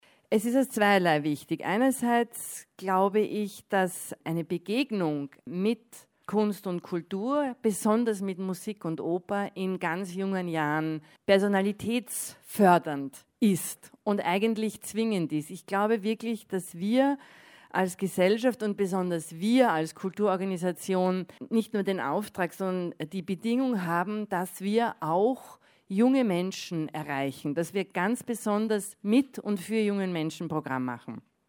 Pressekonferenz Junge Festspiele - O-Töne news